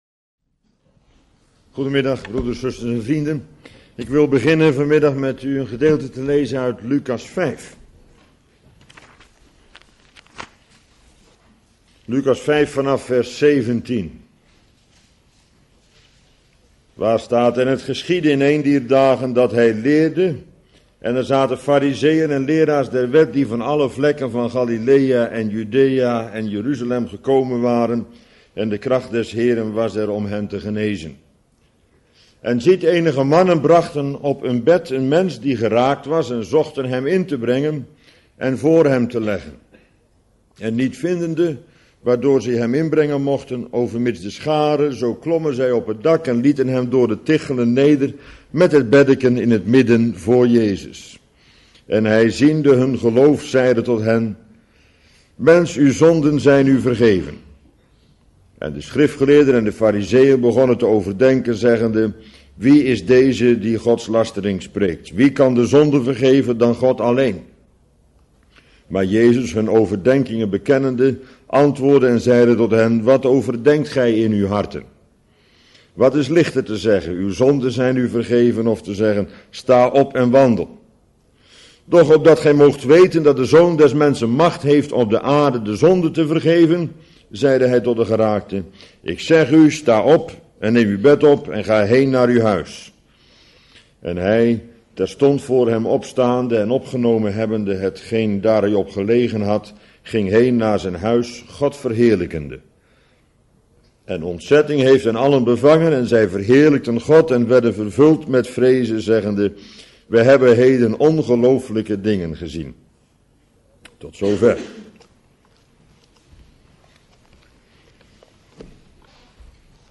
Bijbelstudie lezing onderwerp: De lamme van Kapernaum (Luk.5)